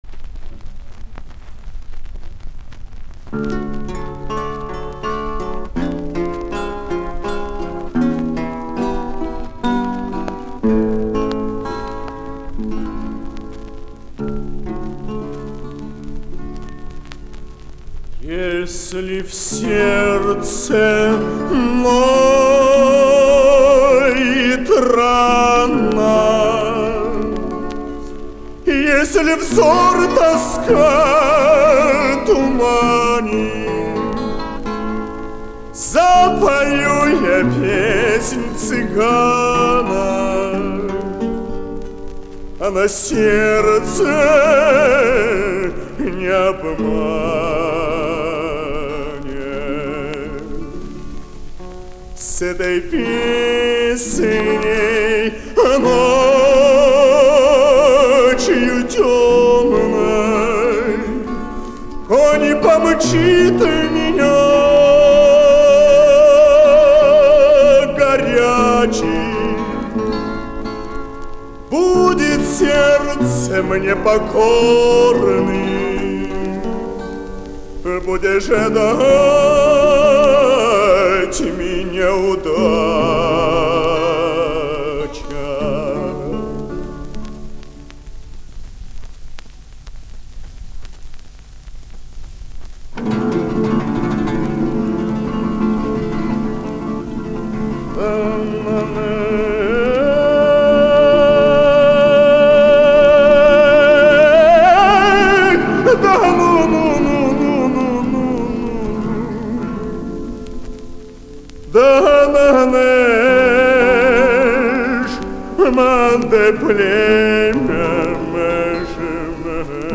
Звуковая страница 7 - Николай Сличенко - артист театра 'Ромэн'.